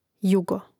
jȕgo jugo